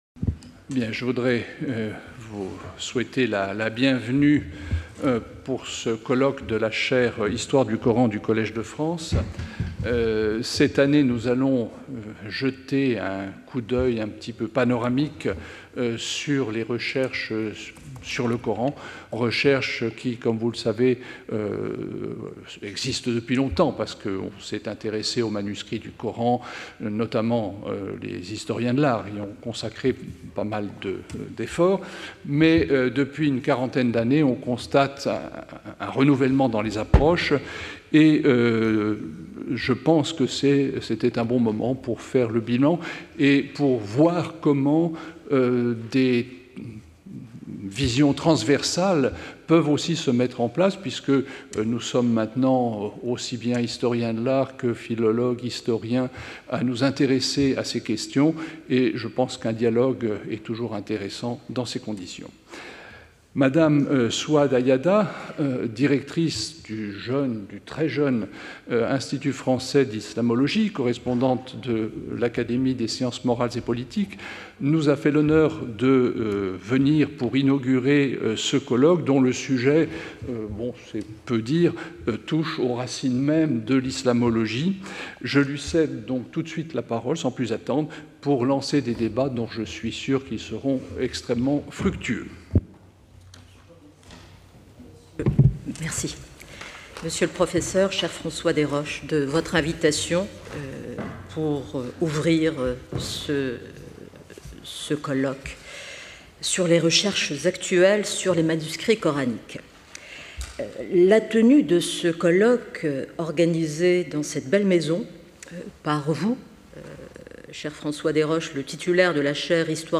Ouverture du colloque | Collège de France
Intervenant(s) François Déroche Professeur du Collège de France